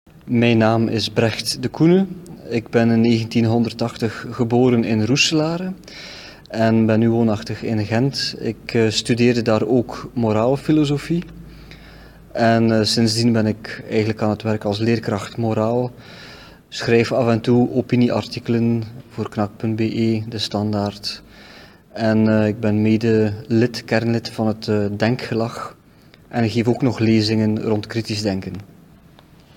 네덜란드어(브라반트 방언) 발음